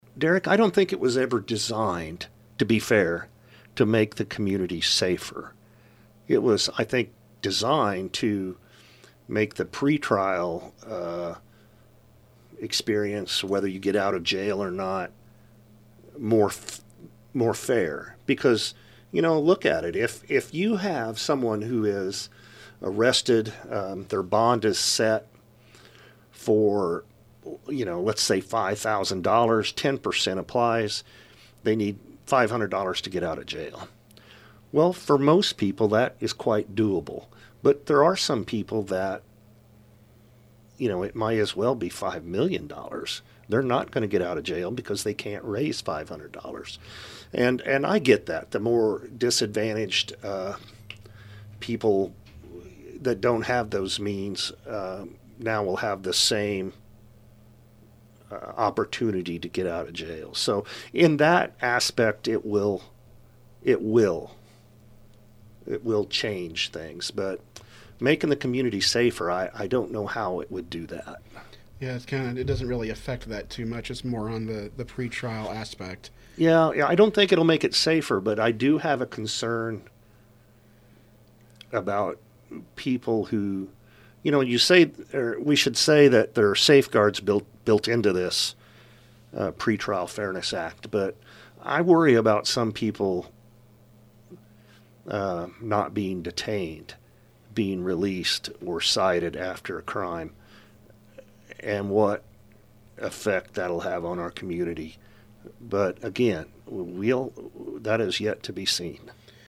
SAFE-T Act Details Explained In Interview With Effingham County Sheriff Paul Kuhns
safe-t-act-interview-part-4.mp3